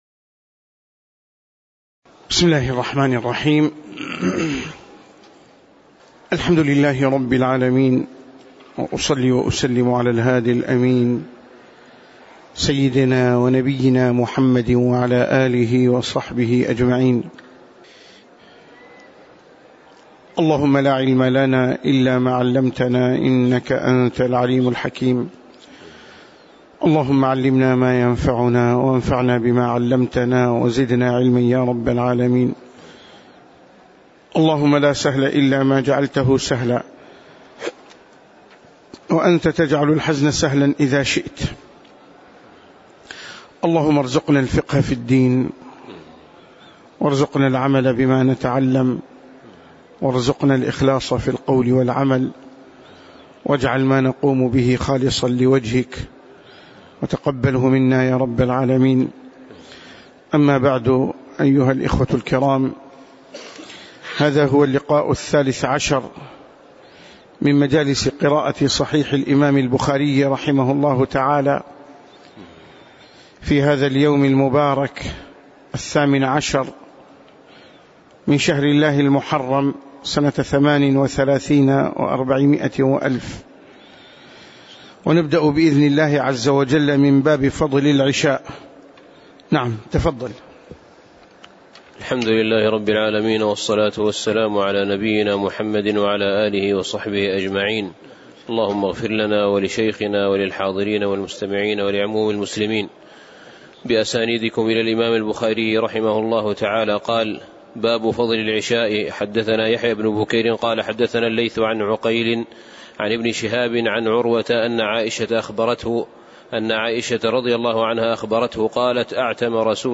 تاريخ النشر ١٨ محرم ١٤٣٨ هـ المكان: المسجد النبوي الشيخ